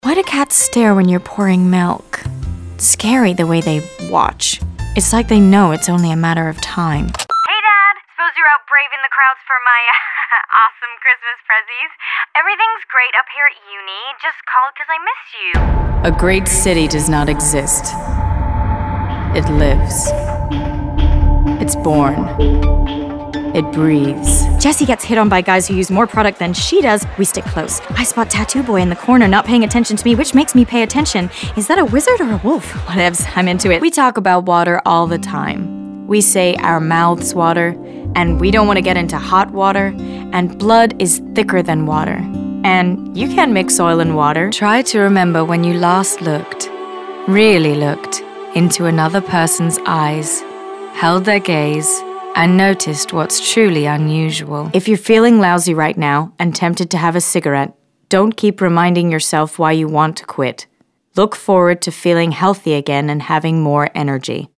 Voice Over Demos
Commercial U.S.